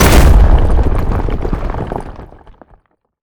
rock_avalanche_landslide_debris_03.wav